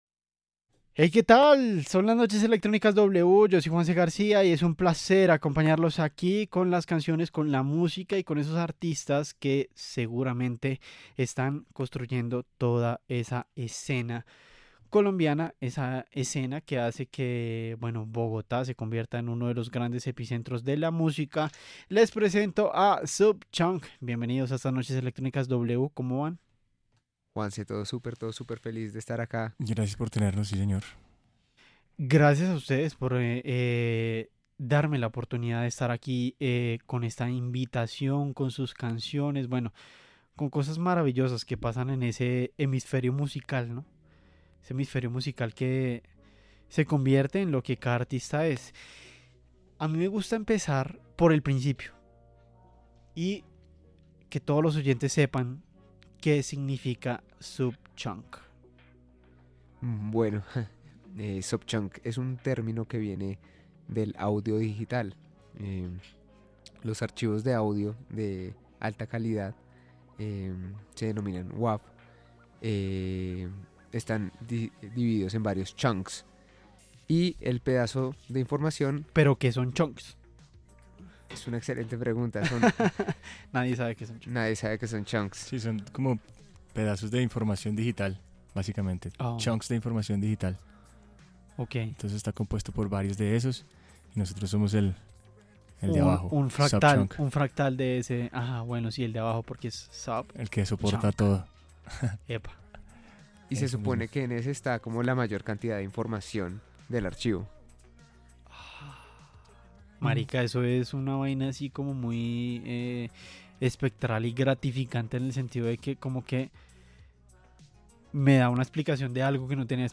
Sub Chunk, dúo de DJs y melómanos, club de Bogotá, pasaron por los micrófonos de Noches Electrónicas W para hablar de lo que ha sido su trayectoria.